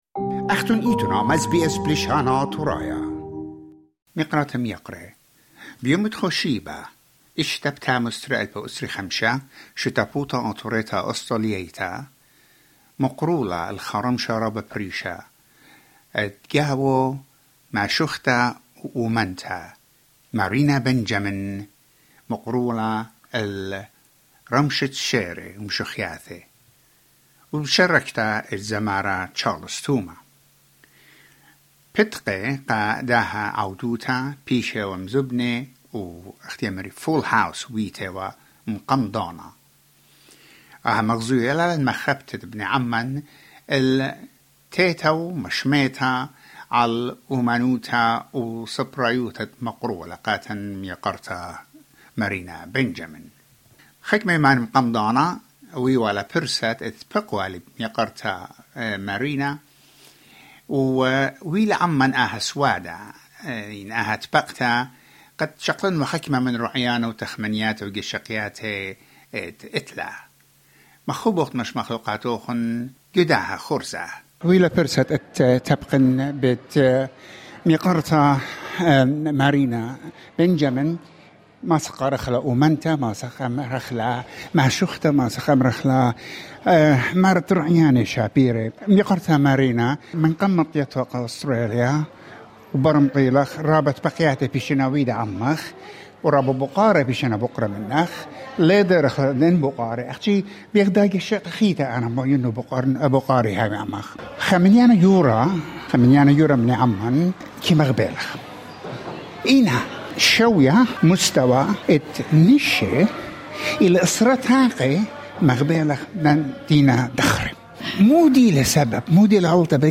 During the official launch of the Ashurbanipal Library